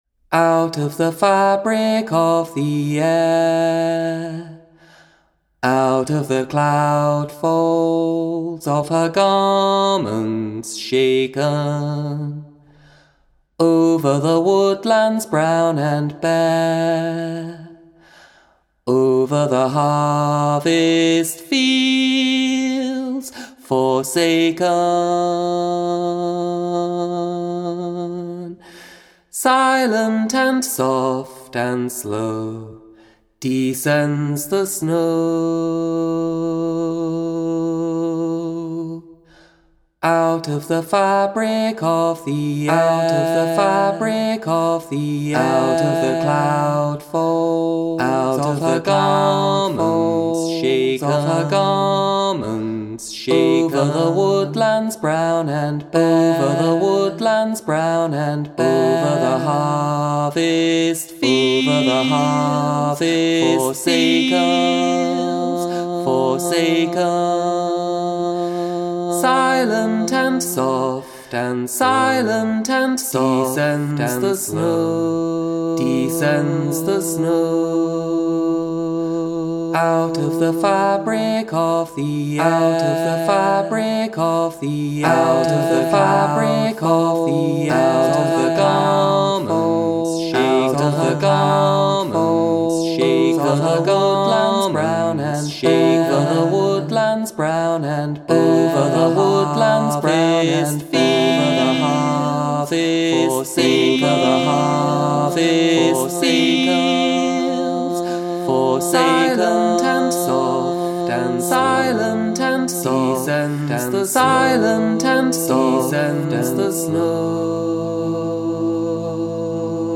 Rounds and Canons